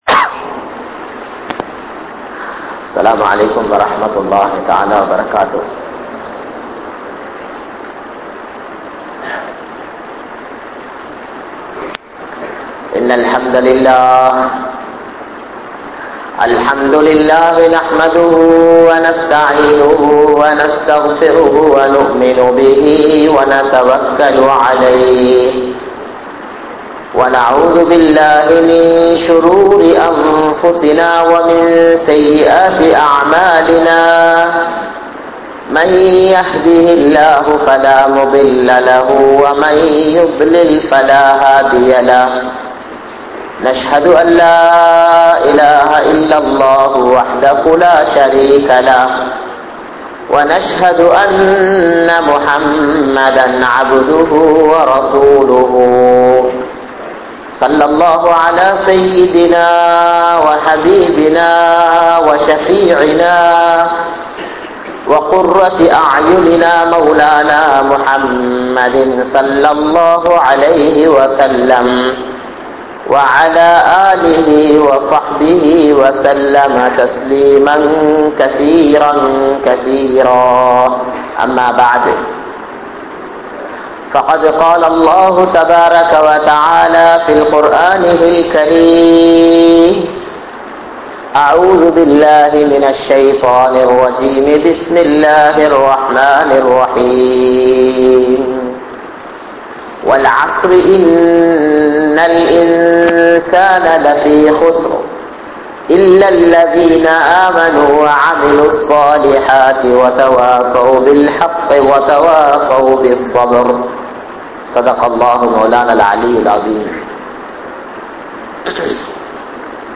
Intha Ummaththin Poruppu (இந்த உம்மத்தின் பொறுப்பு) | Audio Bayans | All Ceylon Muslim Youth Community | Addalaichenai